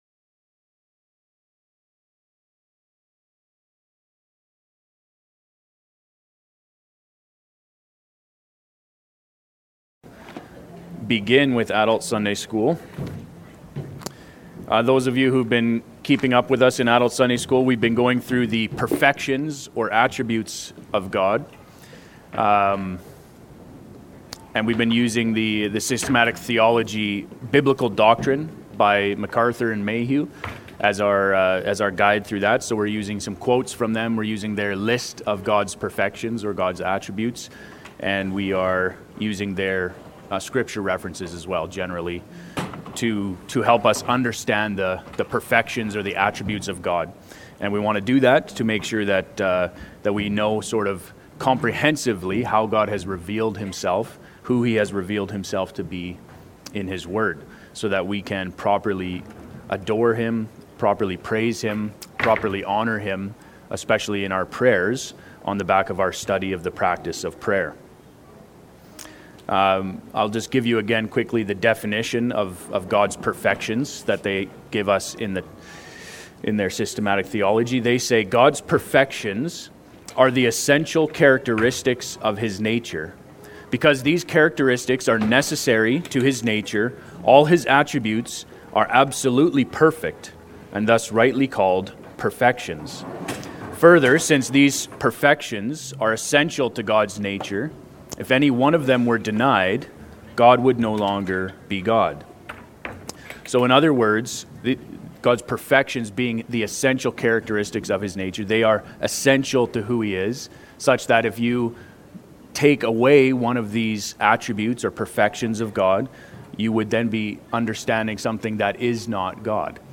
Category: Sunday School